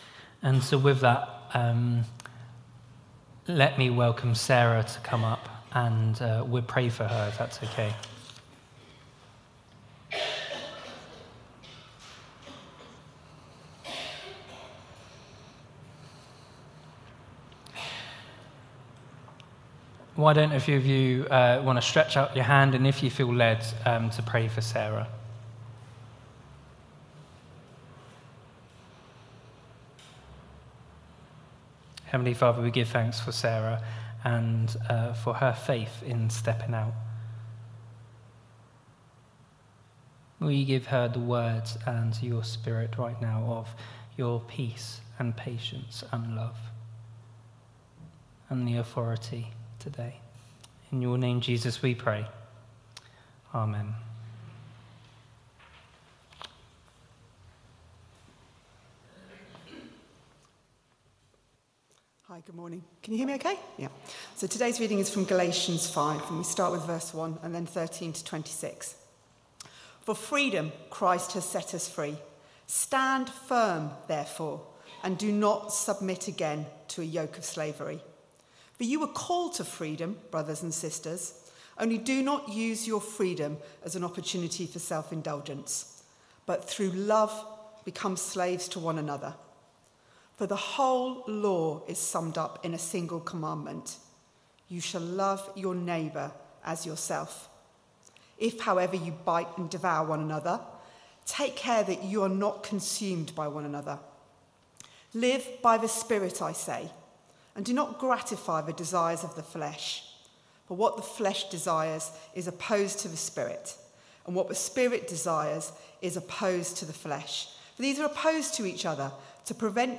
Sermon 17th Sept 2023 11am gathering
We have recorded our talk in case you missed it or want to listen again.
Family Gathering for Worship 11am 17th Sept 2023